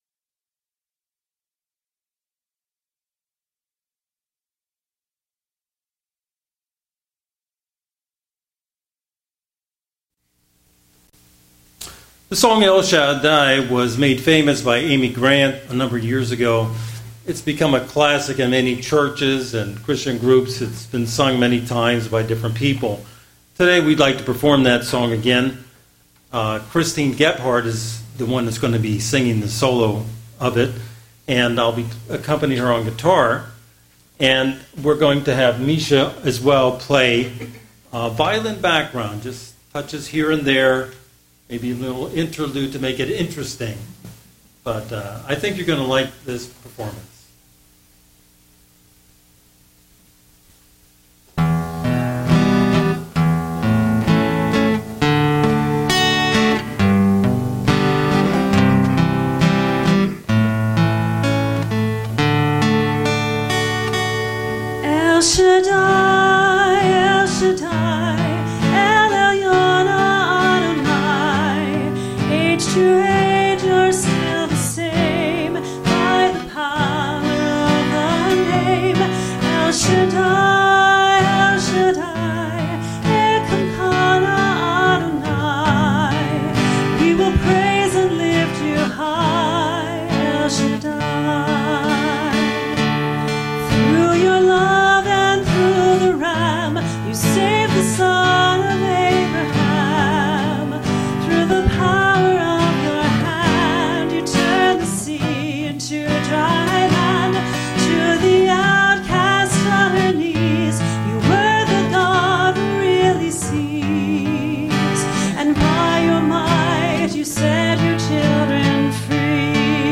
Vocal Video
vocals while I fill in with background
vocals and guitar.